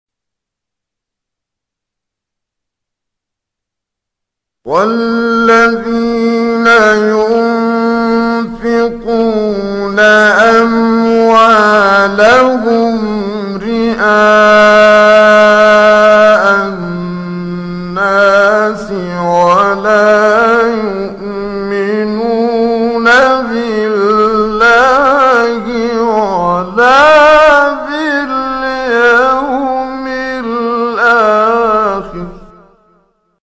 گروه شبکه اجتماعی: فرازهای صوتی از کامل یوسف البهتیمی که در مقام بیات اجرا شده است، می‌شنوید.